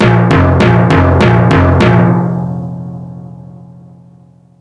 NFF-timpani-march.wav